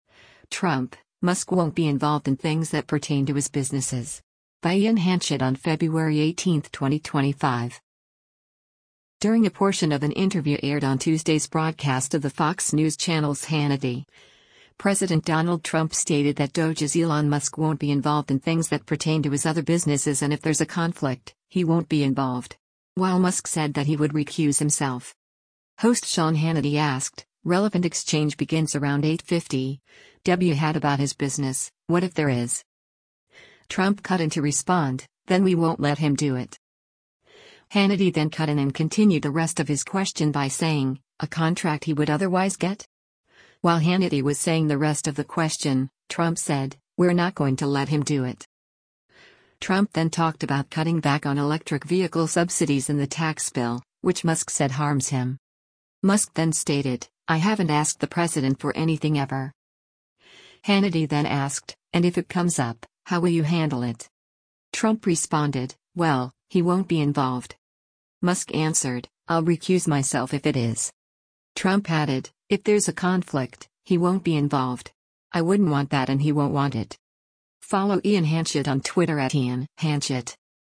During a portion of an interview aired on Tuesday’s broadcast of the Fox News Channel’s “Hannity,” President Donald Trump stated that DOGE’s Elon Musk won’t be involved in things that pertain to his other businesses and “If there’s a conflict, he won’t be involved.”